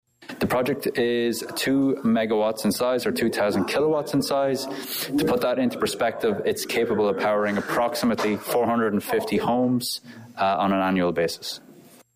(Representatives from Solar Provider Group speak to citizens at Westville’s Public Library Thursday evening,  November 2, 2023.)